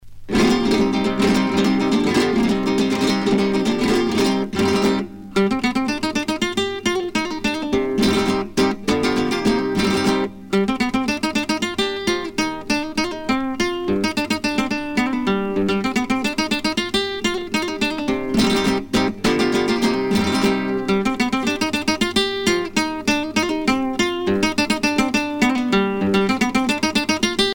danse : sevillana
Pièce musicale éditée